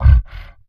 MONSTER_Grunt_Breath_04_mono.wav